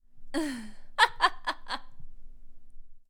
Female Evil Laugh
cackle chortle evil female giggle girl insane laugh sound effect free sound royalty free Funny